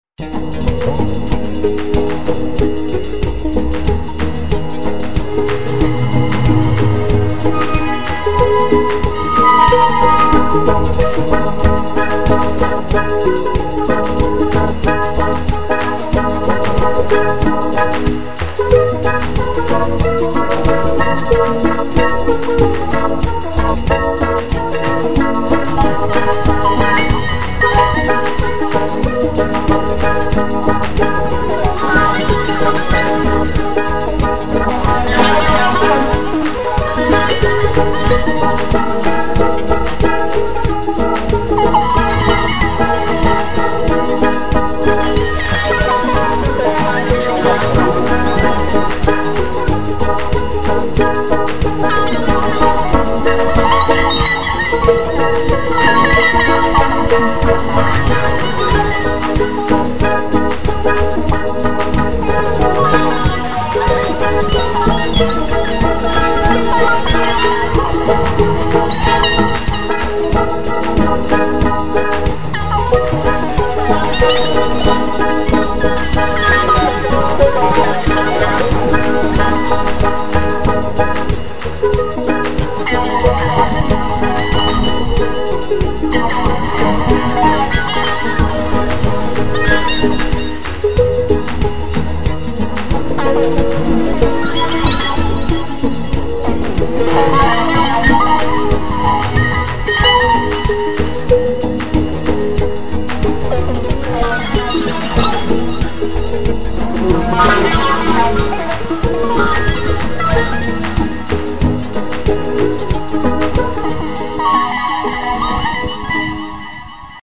First I put the guitar theme through the meat grinder (chopped up, tempo doubled, variation and transpose parameter added). Then I made a sketch of the first four bars of the intro, used it to create a new progression, converted it into an arrangement and then played around a bit with containers.
Has anyone asked for an ambient track with more meditative patterns?